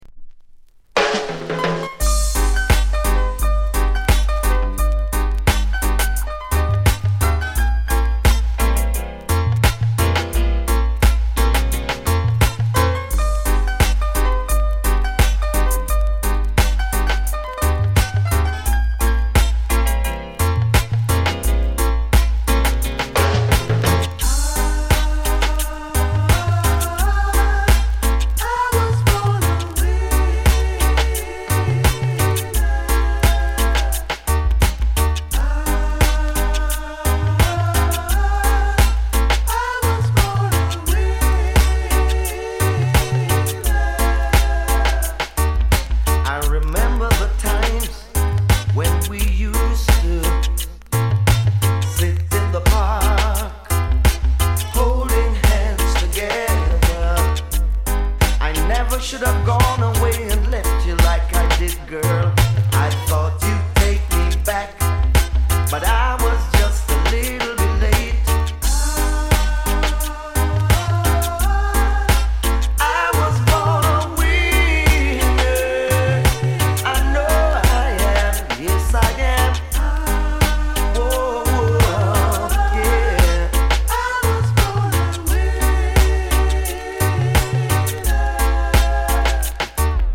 軽いチリ 乗りますが、気になるレベルではありません。